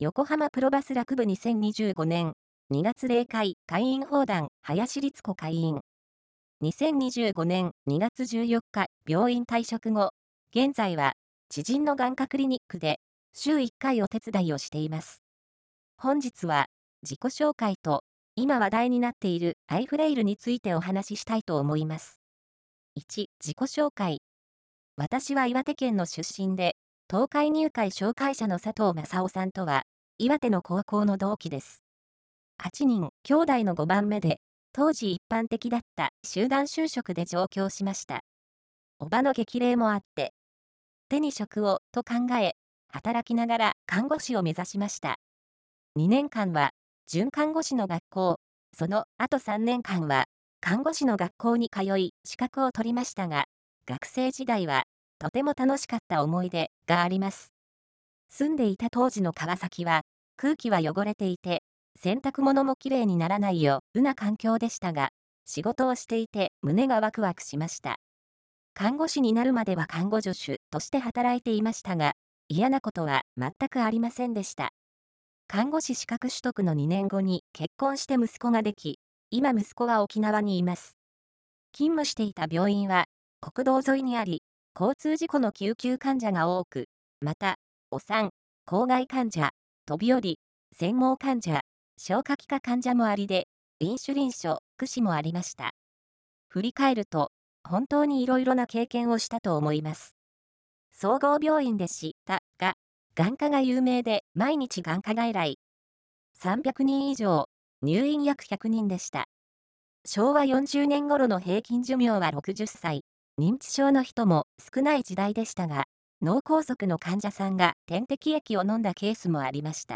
横濱プロバス俱楽部２０２５年 2 月例会 会員放談